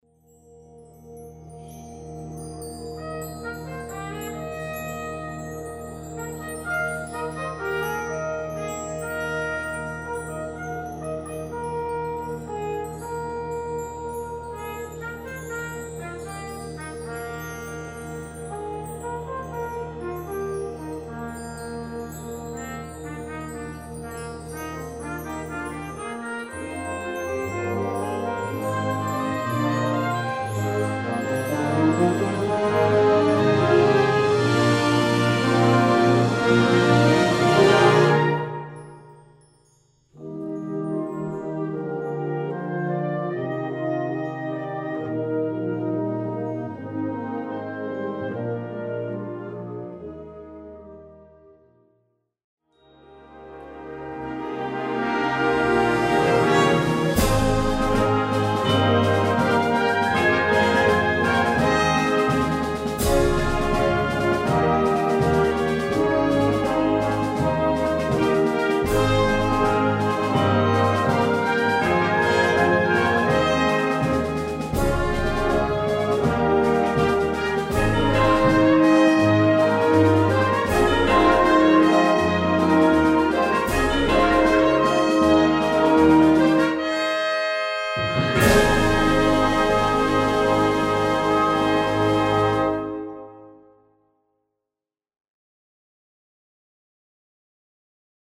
Gattung: Eröffnungswerk
Besetzung: Blasorchester